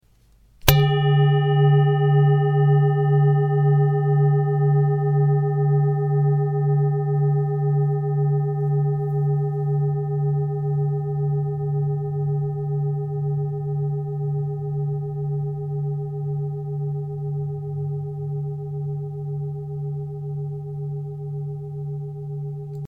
KLANGPROBEN
3 Klangschalen für die Klangmassage
Sehr gute Klangqualität - sehr lange anhaltender Klang
Grundton 145,39 hz
1. Oberton 403,69 hz